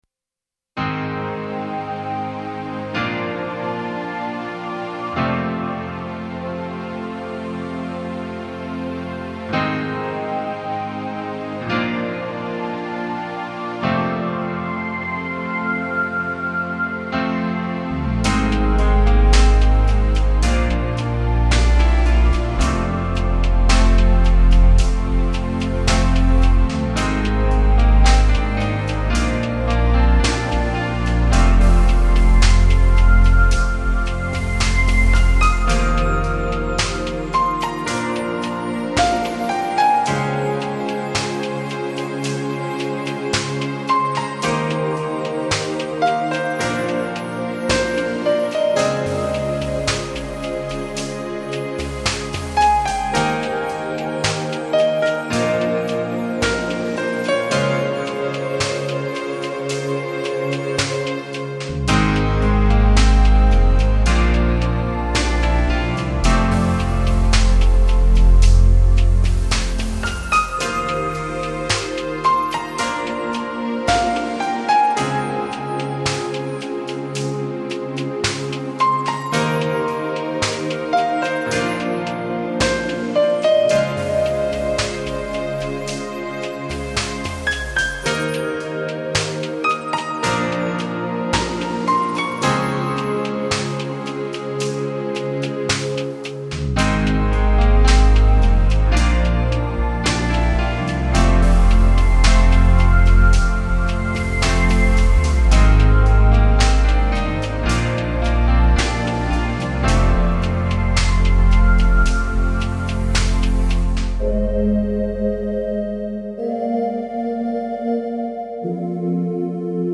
Genre..........: New Age, Ambient